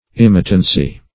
Search Result for " imitancy" : The Collaborative International Dictionary of English v.0.48: Imitancy \Im"i*tan*cy\, n. [From L. imitans, p. pr. of imitare.] Tendency to imitation.